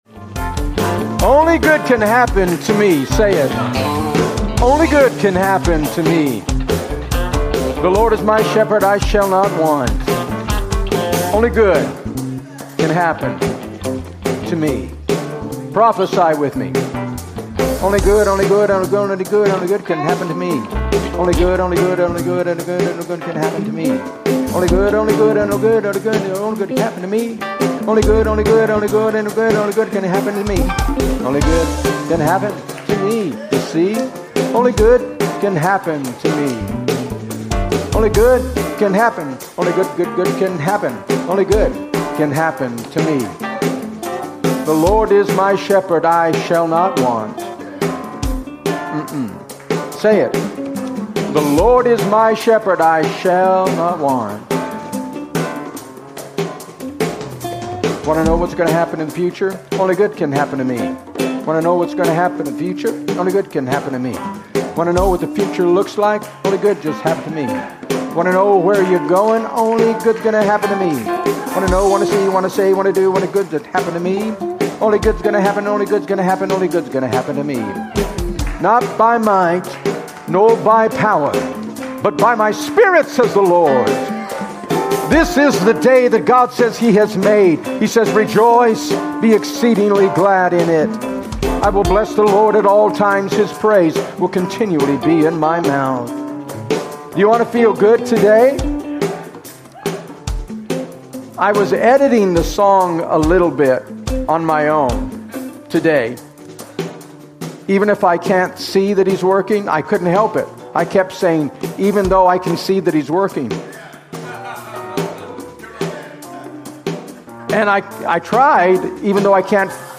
Sermon: Full Service: